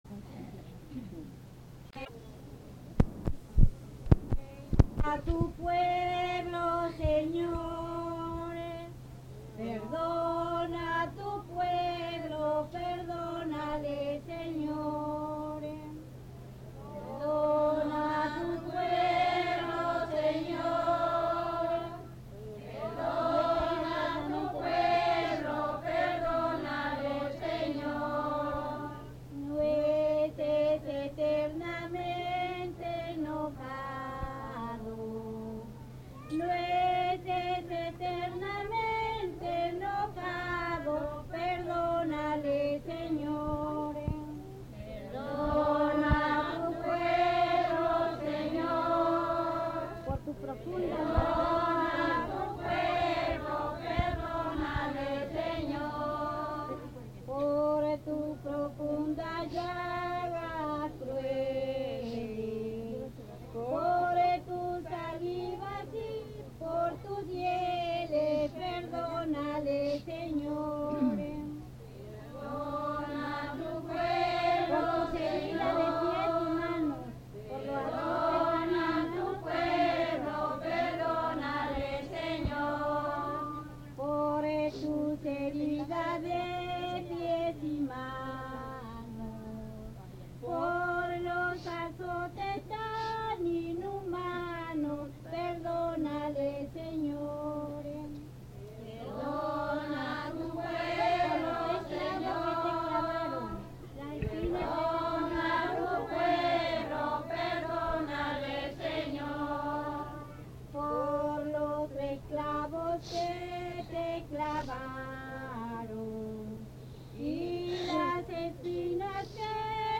Fiesta del Señor Santiago